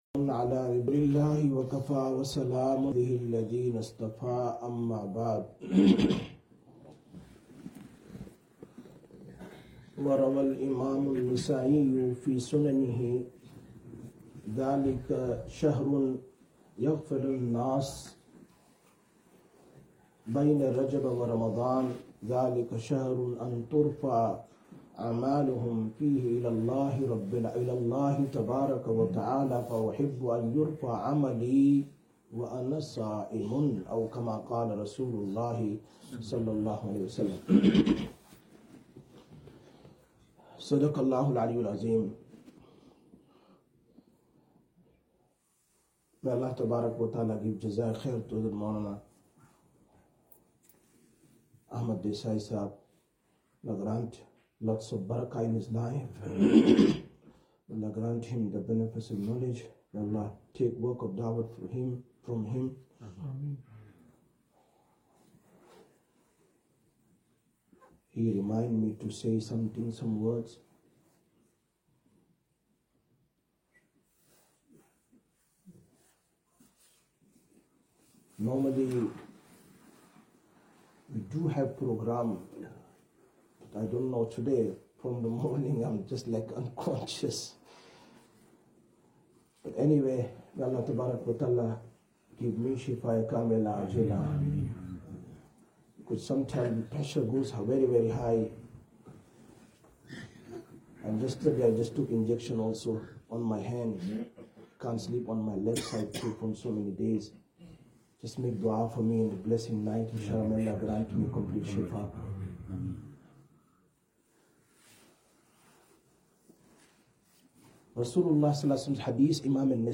13/02/2025 Shab e Barat Bayaan, Masjid Quba